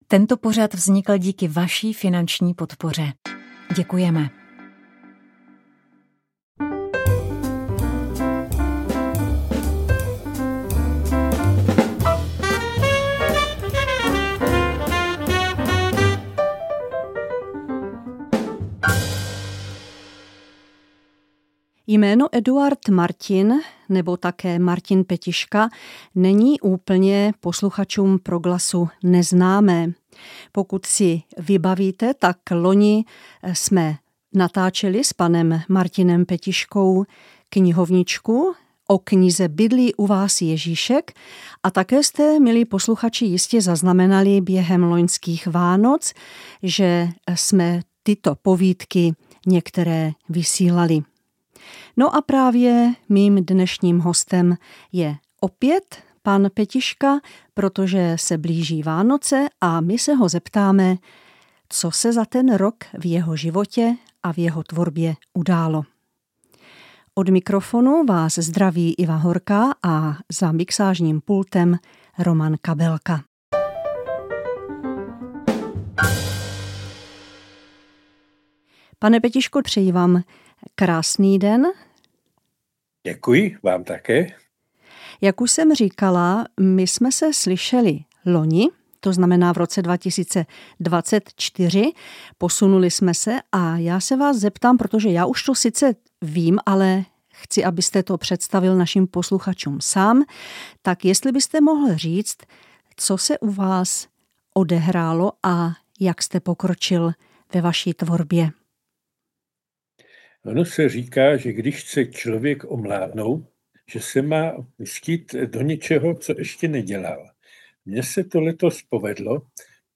V závěru nebude chybět několik ukázek básní, které nám autorka přednese.